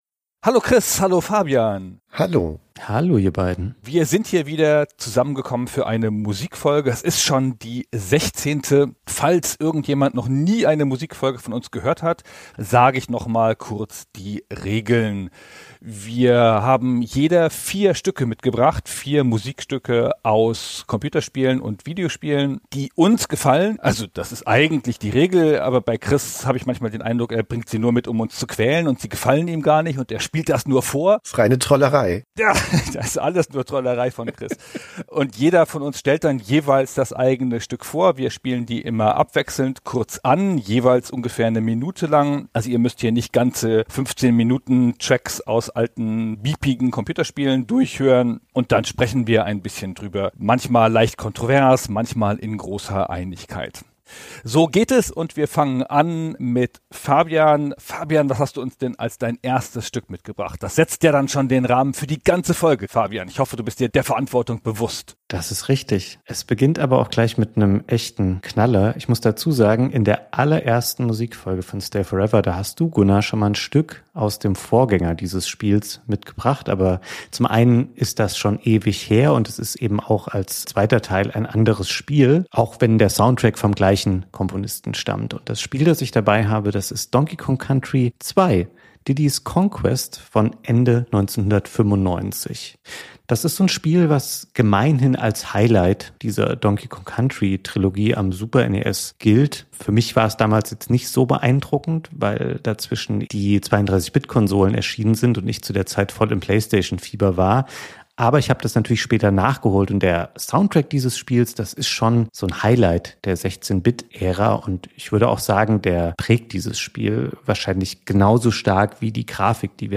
Die Stücke werden vom jeweiligen Aussucher angespielt und vorgestellt, die anderen beiden kommentieren mehr oder minder wohlwollend.